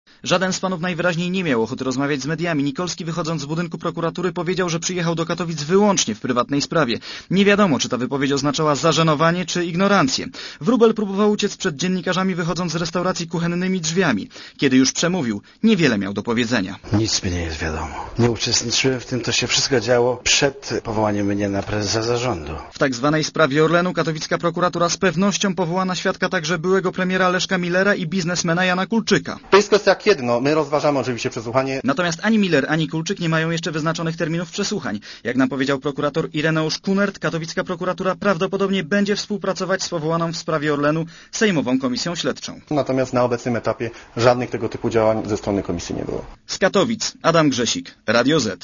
reportera Radia ZET